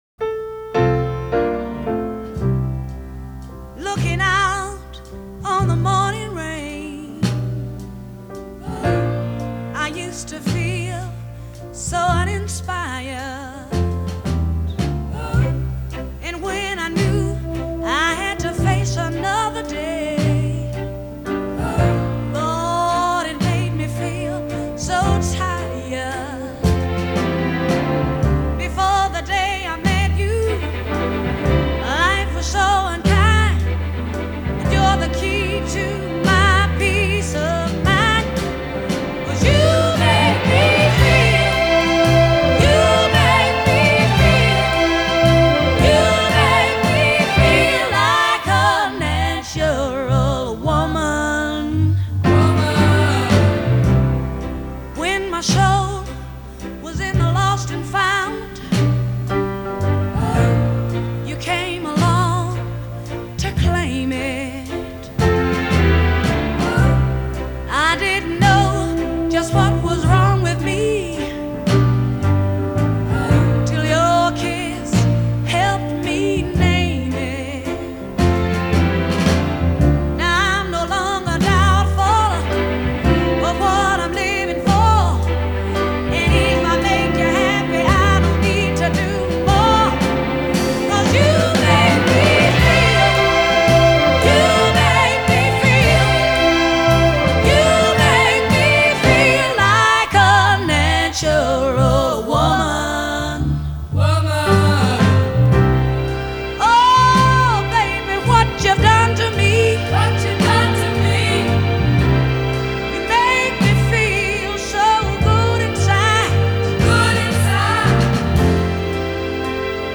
piano, bass and drums
It aches. It soars.
It’s pop music. It’s soul music. It’s the blues.
Something happened in that Memphis studio.